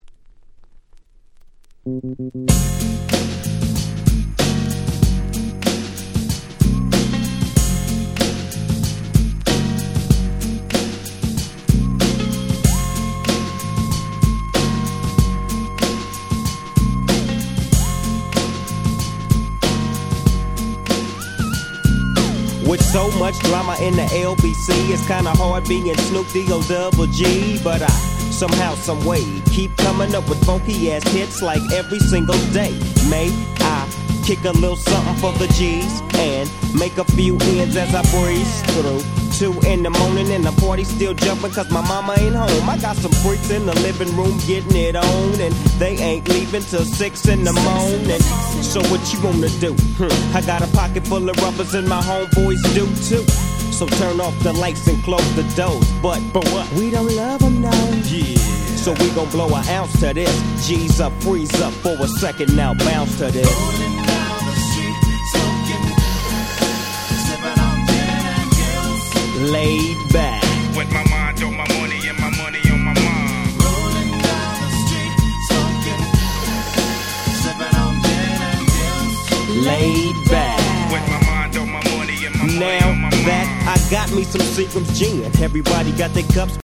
93' Super Hit Hip Hop !!